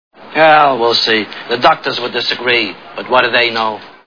The Godfather Part II Sound Bites (Page 2 of 4)